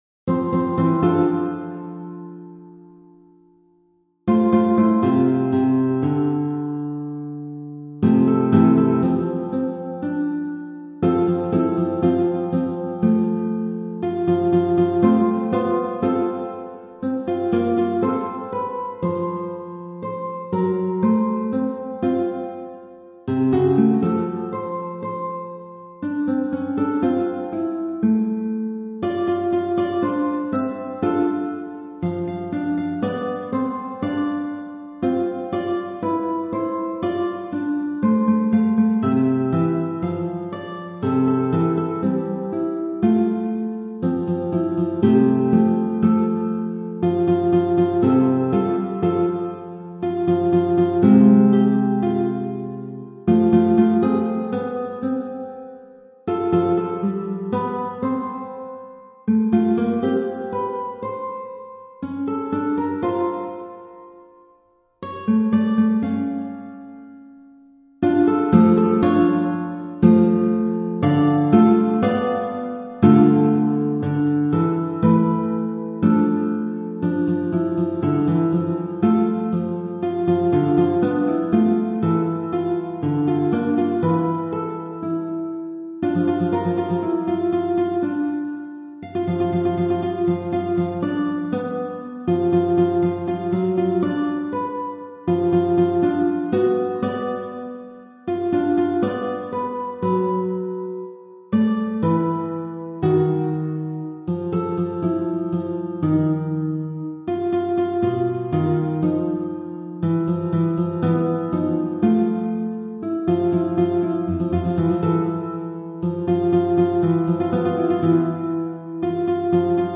Dans mon expérience ci-dessous, les cent mesures (cinquante sur chaque portée, en canon) valent 100.
Les seuls do bémols sont aux deux dernières mesures.
Mélodie engendrée par les mots ci-dessus